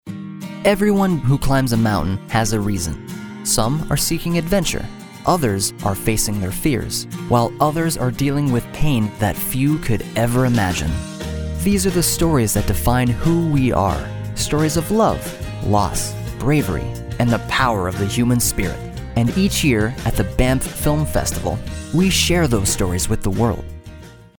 Male
Yng Adult (18-29), Adult (30-50)
Radio Commercials